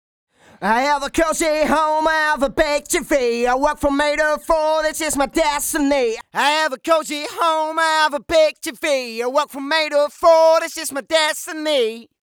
home_vs_studio-wav.90016